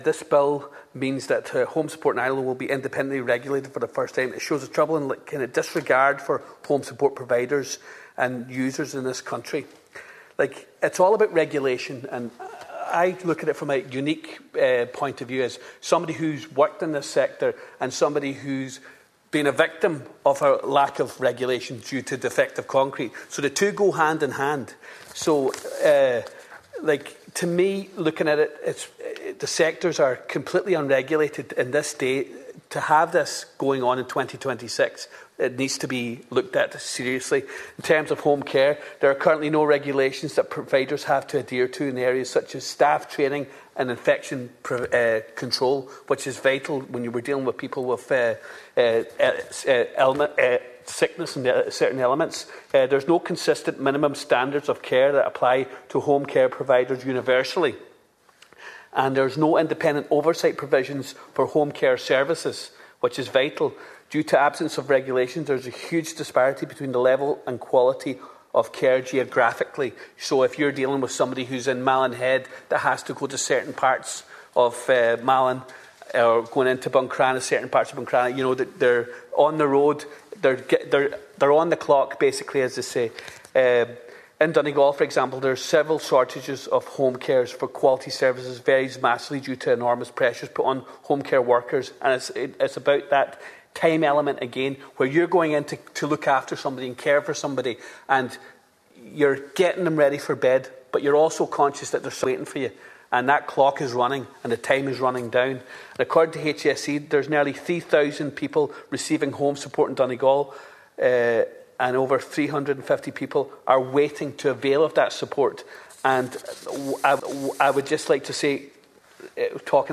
You can listen to Deputy Ward’s full submission here –